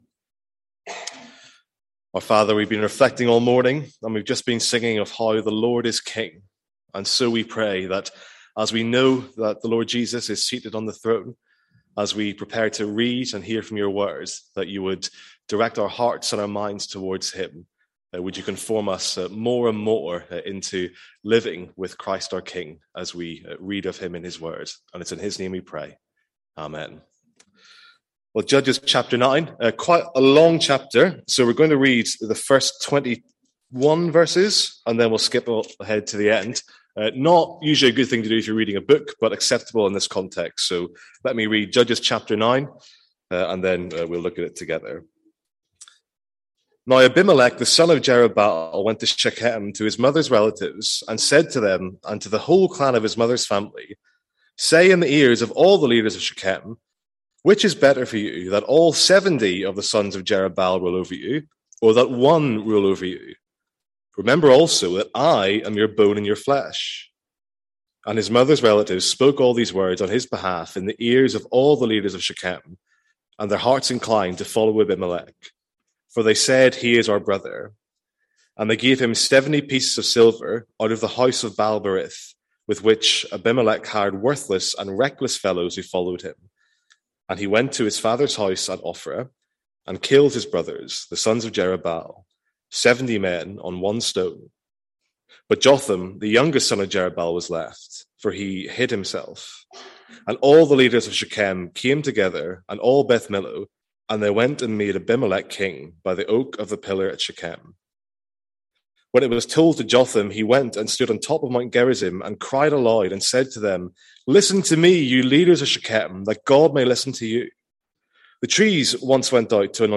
Sermons | St Andrews Free Church
From our morning series in the book of Judges.